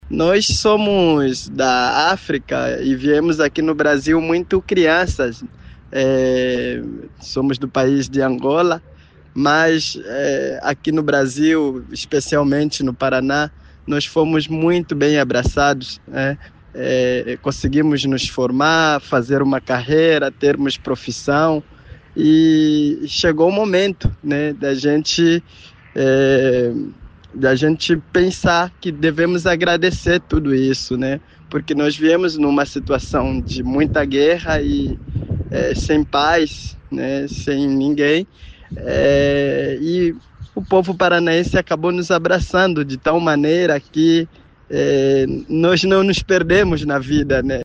SONORA-CONCERTO-ANGOLA-02-BO.mp3